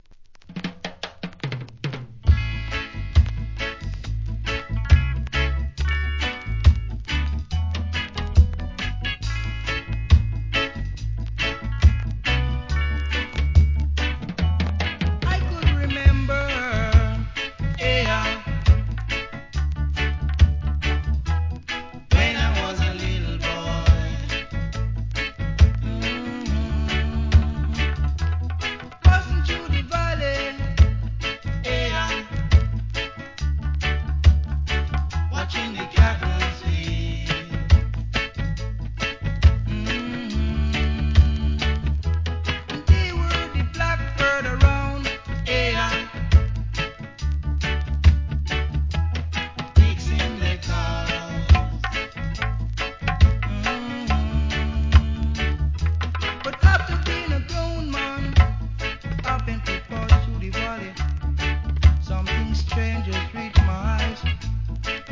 REGGAE
気持ちいい曲です♪